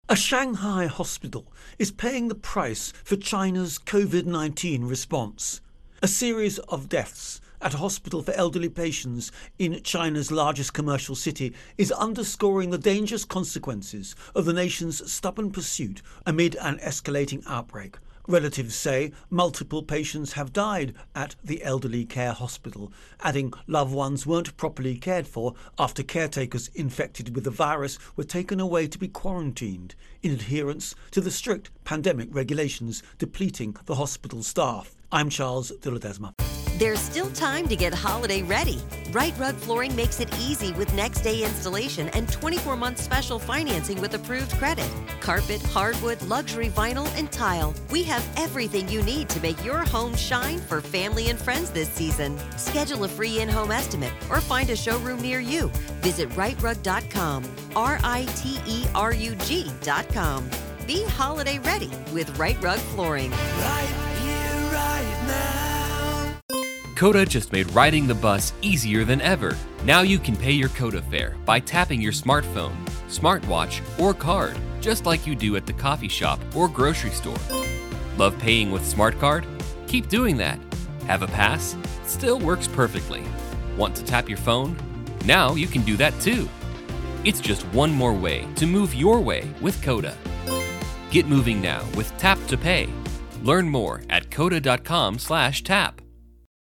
Virus Outbreak-China-Shanghai intro and voicer